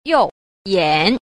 4. 右眼 – yòu yǎn – hữu nhãn (mắt phải)